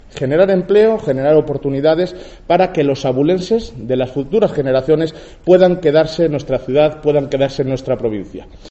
Alcalde de Ávila. Centro de Transferencia del Conocimiento